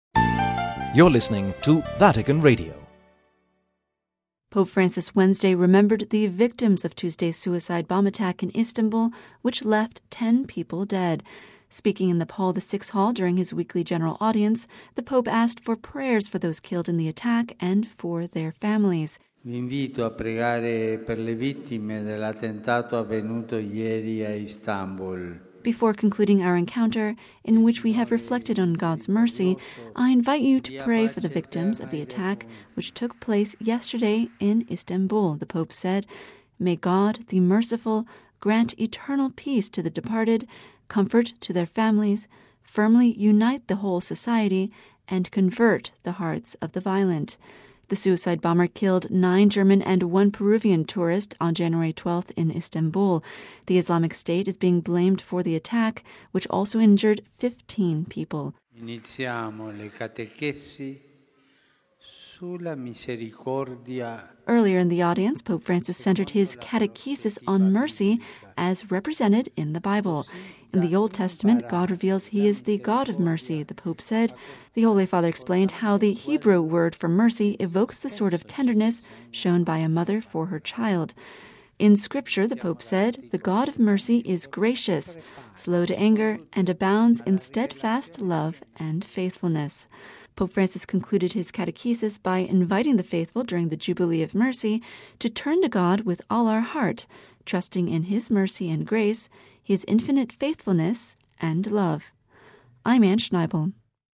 Speaking in the Vatican's Paul VI Hall during his weekly general audience, the Pope asked for prayers for those killed in the attack, and for their families.